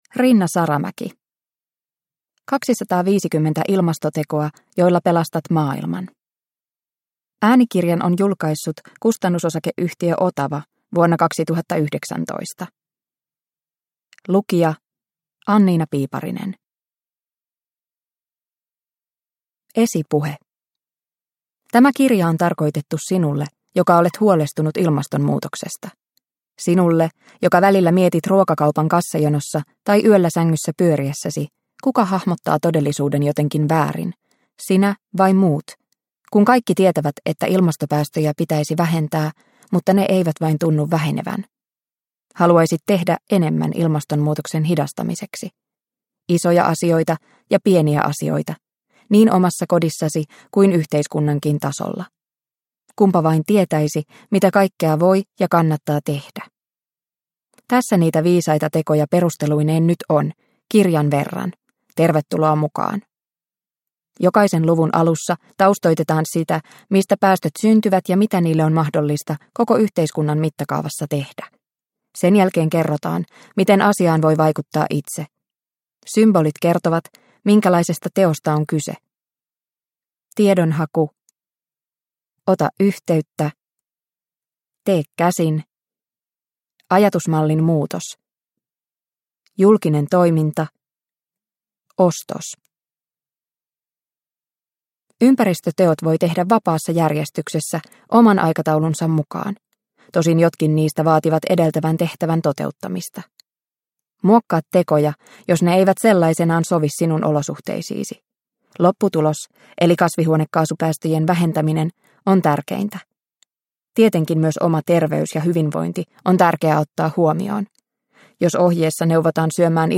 250 ilmastotekoa, joilla pelastat maailman – Ljudbok – Laddas ner